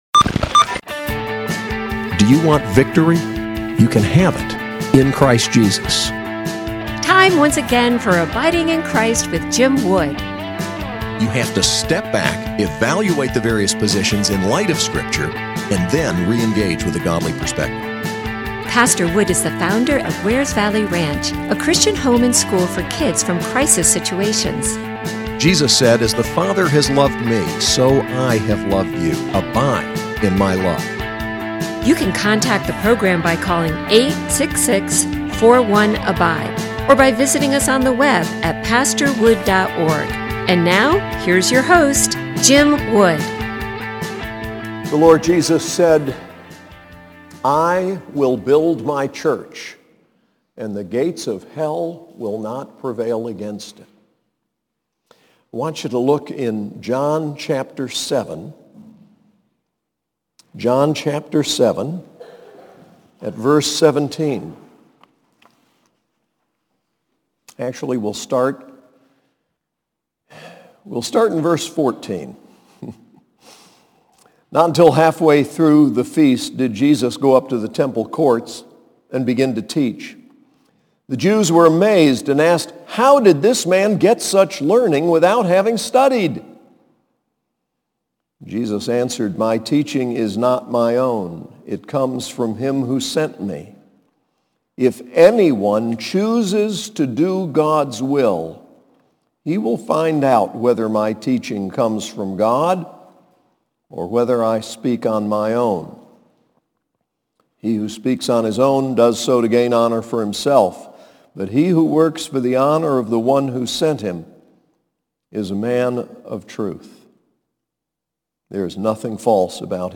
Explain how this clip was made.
SAS Chapel: Reformation, part 4